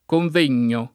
convegno [ konv % n’n’o ] s. m.